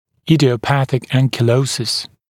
[ˌɪdɪə(u)ˈpæθɪk ˌæŋkɪˈləusɪs][ˌидио(у)ˈпэсик ˌэнкиˈлоусис]идиопатический анкилоз, анкилоз неизвестного происхождения